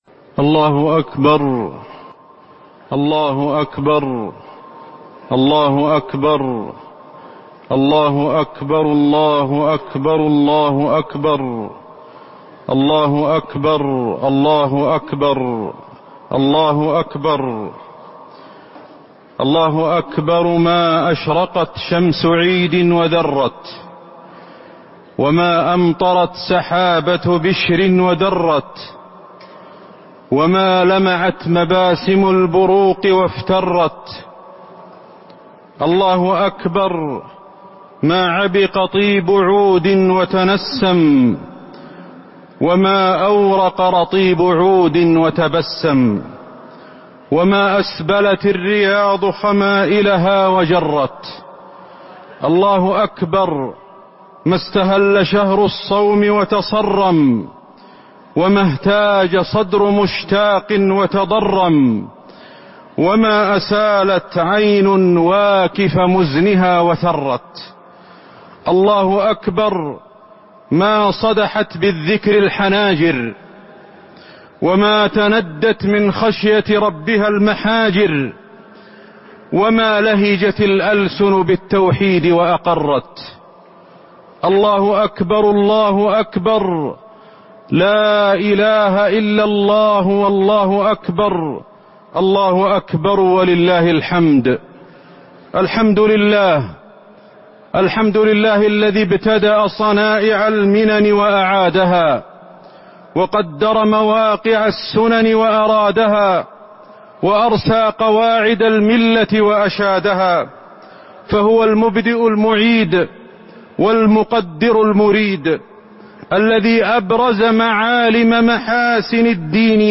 خطبة عيد الفطر- المدينة - الشيخ أحمد الحذيفي
تاريخ النشر ١ شوال ١٤٤٥ هـ المكان: المسجد النبوي الشيخ: فضيلة الشيخ أحمد بن علي الحذيفي فضيلة الشيخ أحمد بن علي الحذيفي خطبة عيد الفطر- المدينة - الشيخ أحمد الحذيفي